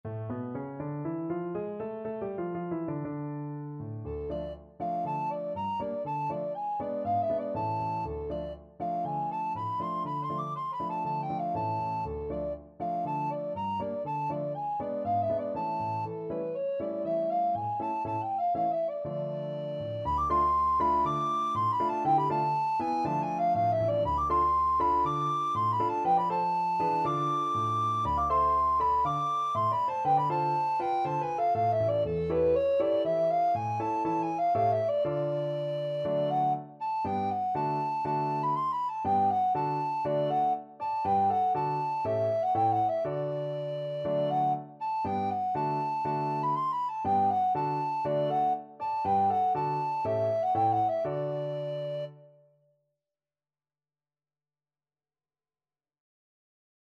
Alto Recorder
Traditional Music of unknown author.
2/4 (View more 2/4 Music)
A minor (Sounding Pitch) (View more A minor Music for Alto Recorder )
Allegro (View more music marked Allegro)